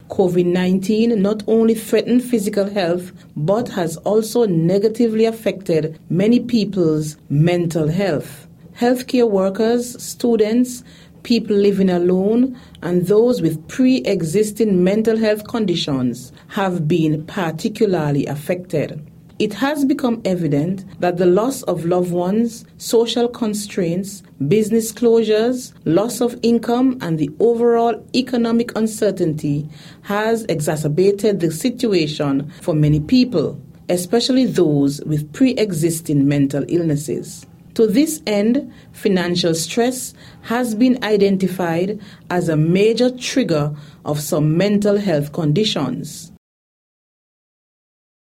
The Junior Minister of Health  on Nevis, is Hazel Brandy-Williams.
In her address to mark the occasion, she stated that the theme serves as “a call to action for all of us”. The Minister also spoke of measures to employ, which can aid in good mental health.